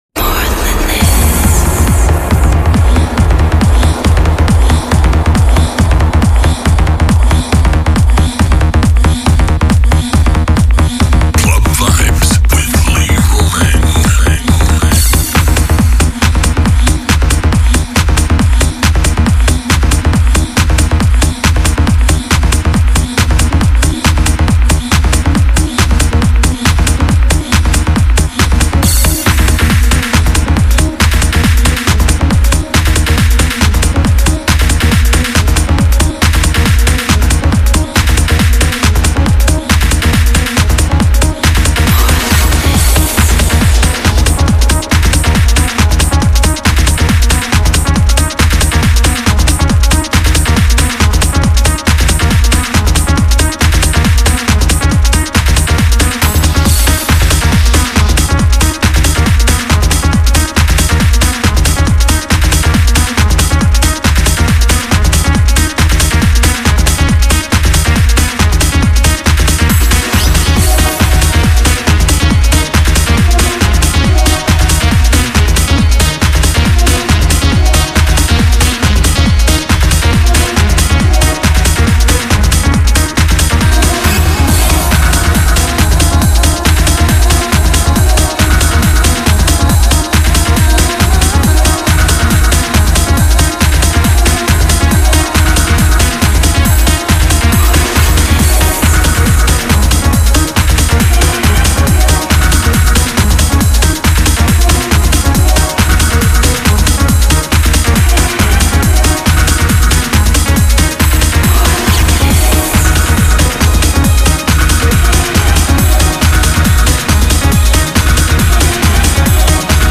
Need some pumping tunes